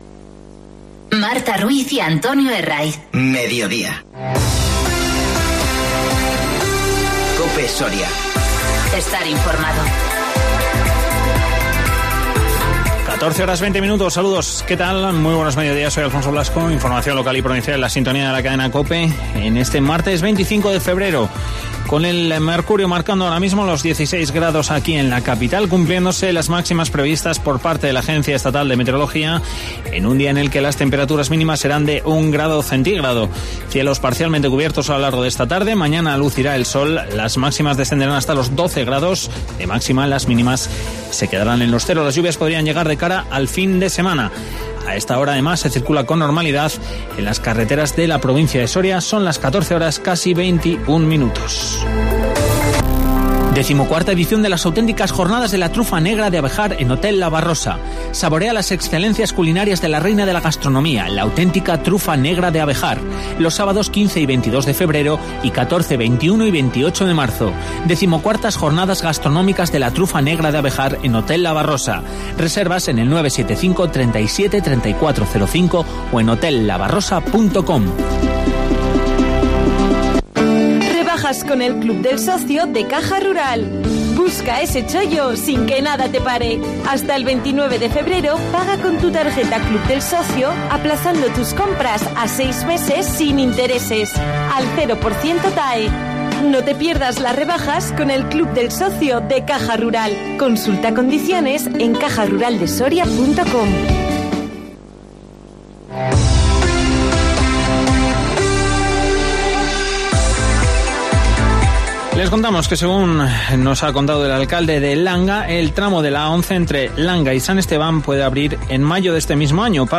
Informativo 25-02-20